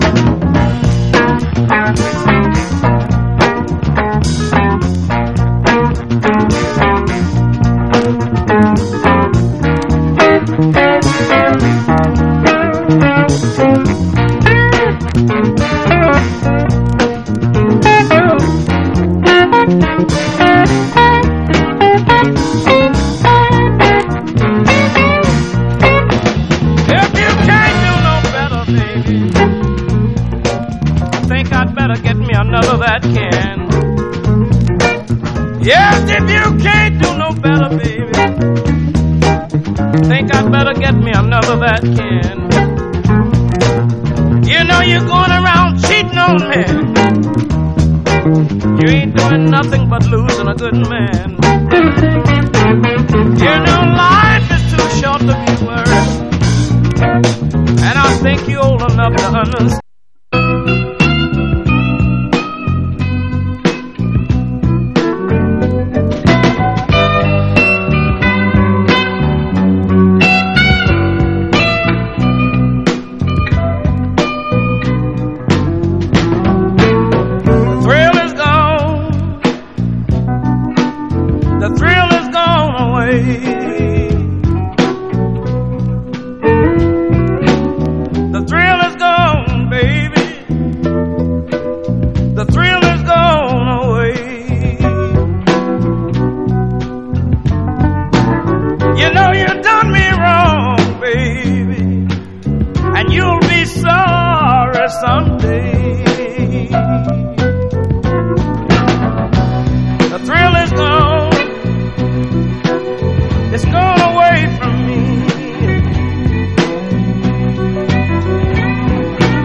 シカゴ・ブルース・レジェンド！